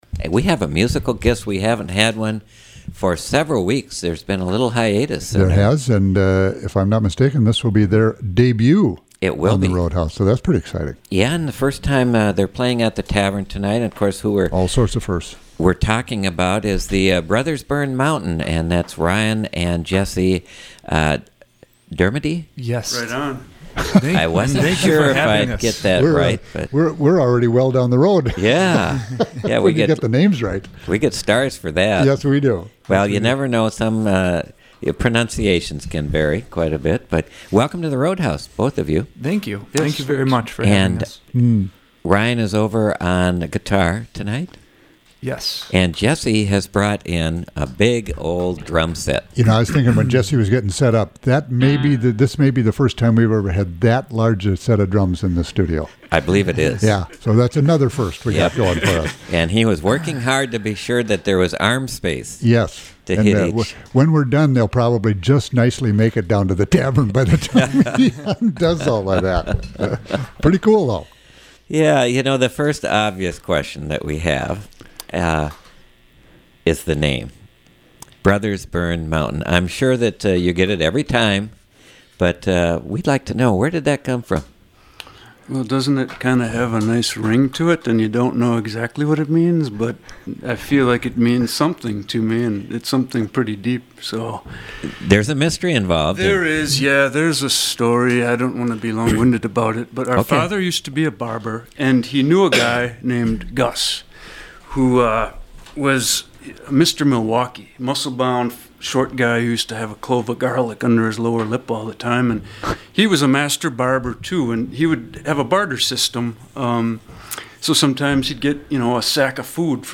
Live Music Archive